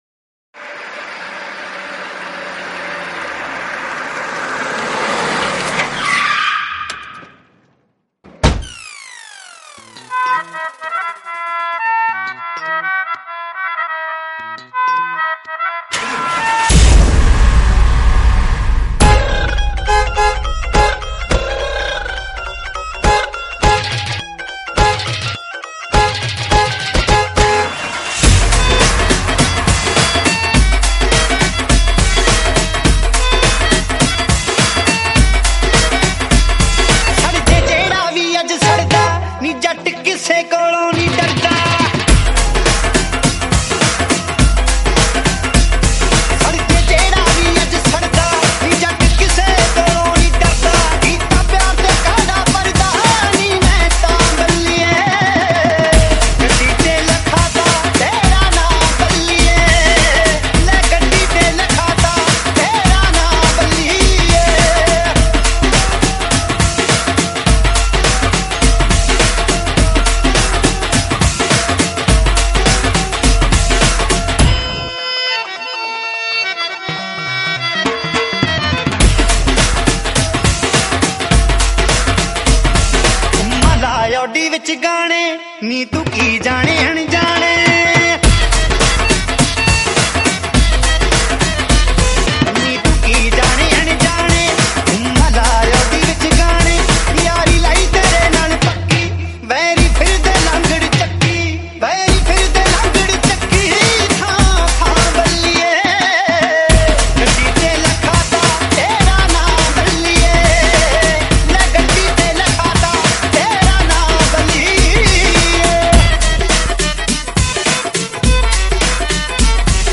Category: UK Punjabi
Remix